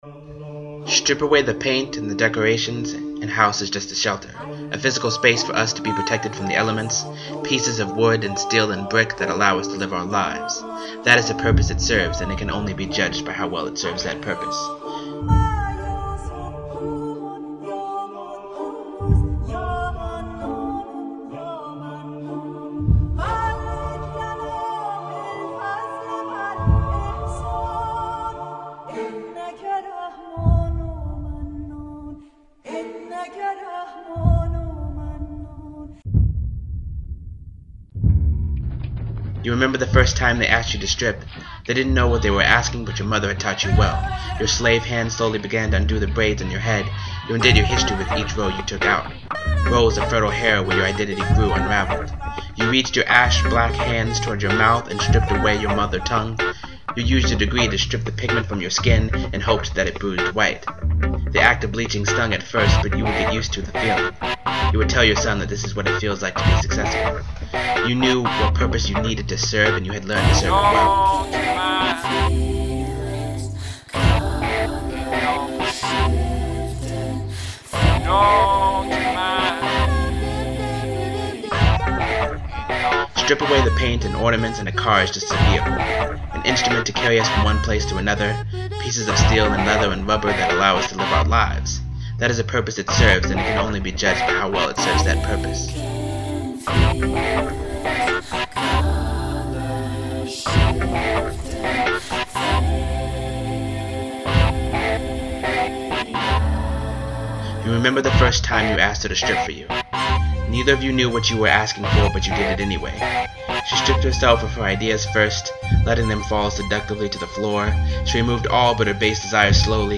This latest poem is less a poem and more a audio art experience? The Poem is called strip or remover of difficulties or the things they ask you to strip away.